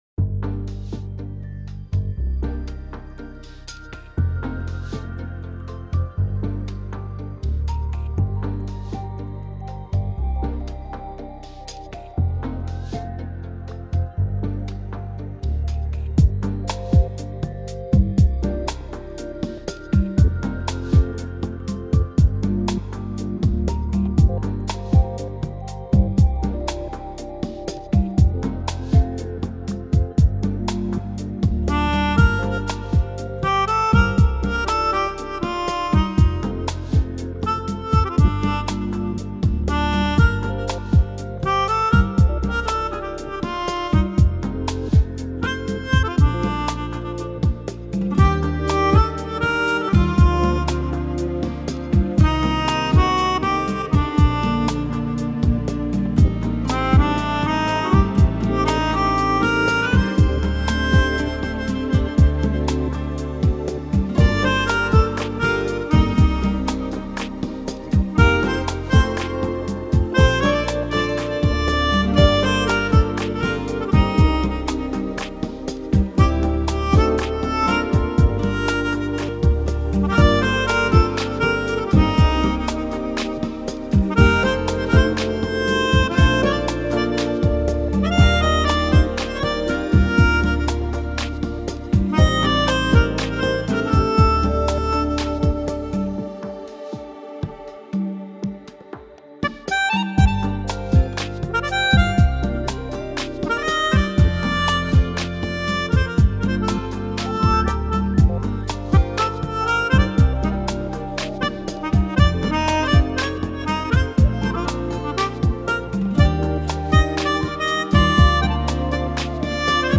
Инструменталка